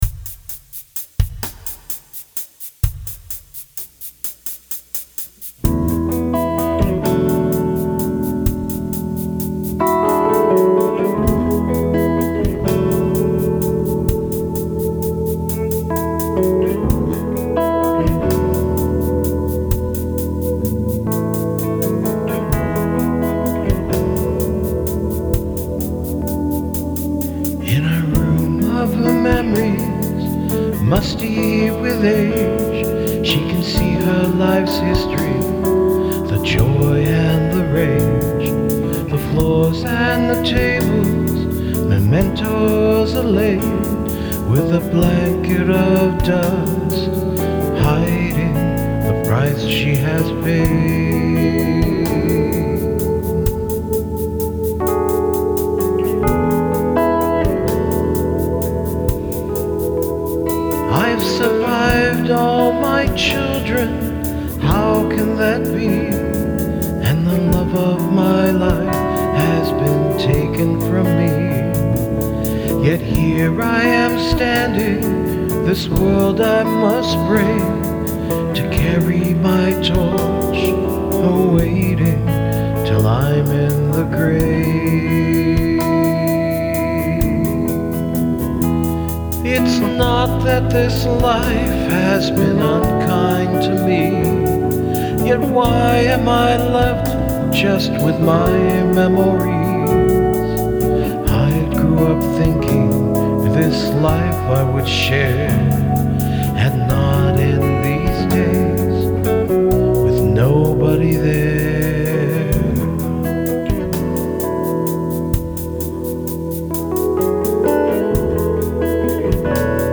For a long time, I've been on a rock kick; specifically, sacred rock (I know, sounds like an oxymoron).
Yeah, it’s a pretty sad theme, but I wanted to capture more than just the bitterness.
Keyboard: Fender-Rhodes Software Model Bass: Ibanez G10